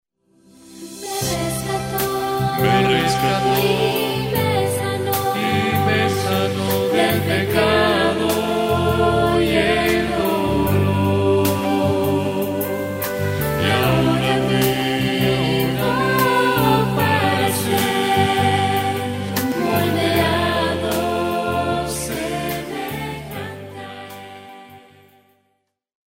llena de adoración y reverencia